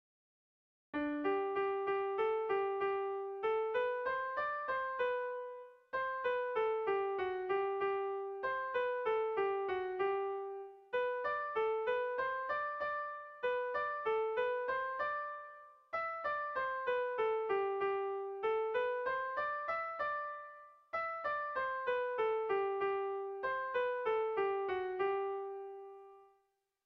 Air de bertsos - Voir fiche   Pour savoir plus sur cette section
Tragikoa
Hamarreko txikia (hg) / Bost puntuko txikia (ip)
ABD..